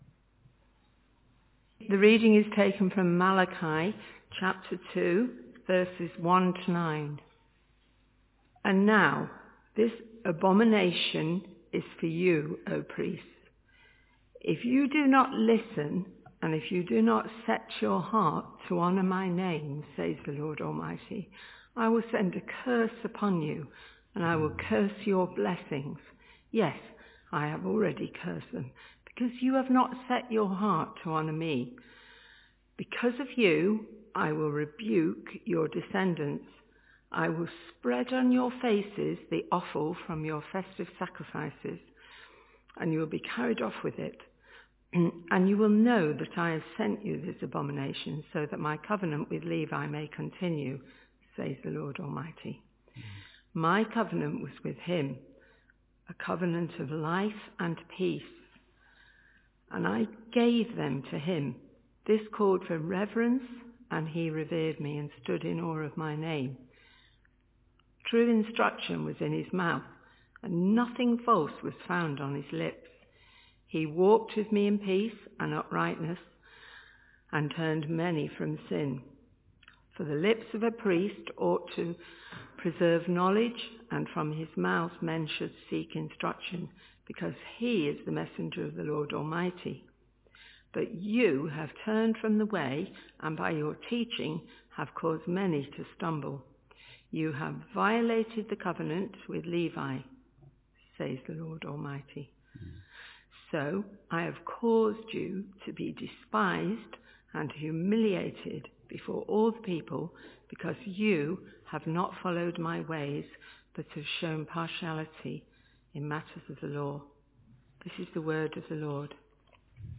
Talk starts after reading at 2.00